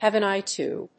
hàve an éye to…